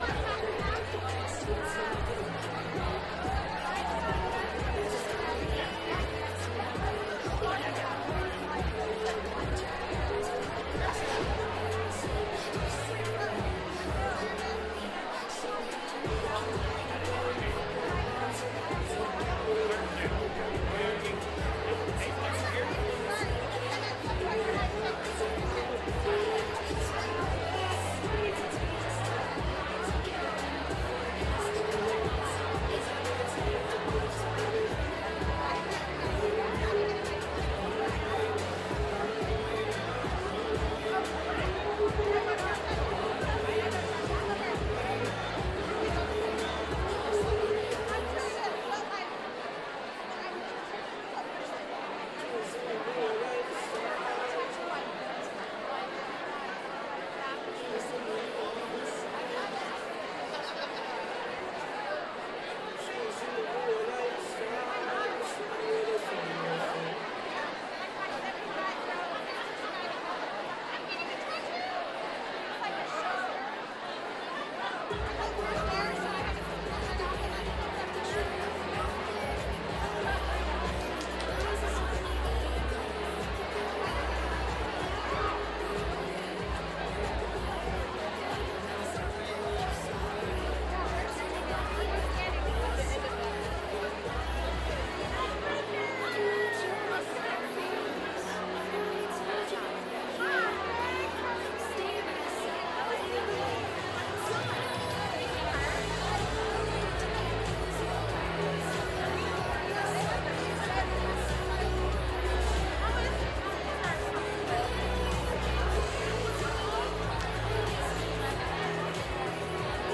lifeblood: bootlegs: 2022-10-19: house of blues - houston, texas
live stream on twitch
01. preshow crowd noise (6:02)